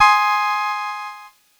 Cheese Chord 14-G#3.wav